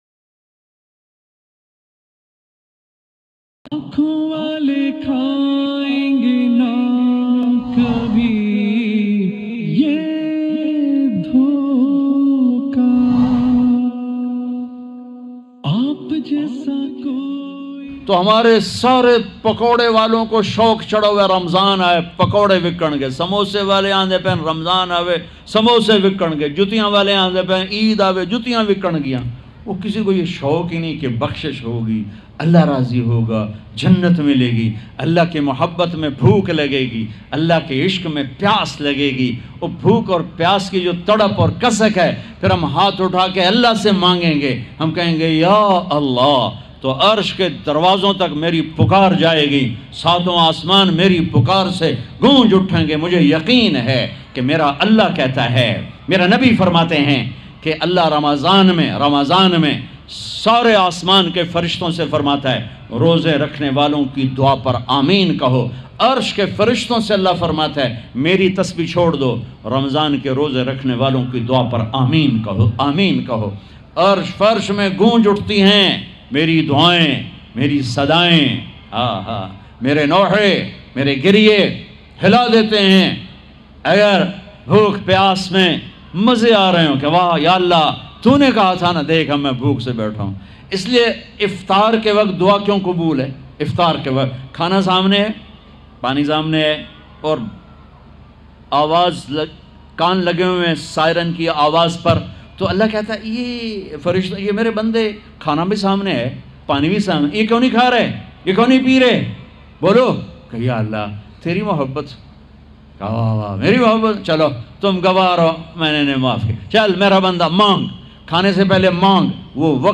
Iftari Main 3 Badi Ghaltiaan Moulana Tariq Jameel bayan
Iftari Main 3 Badi Ghaltiaan Ramzan Maulana Tariq Jameel latest bayan mp3 free download.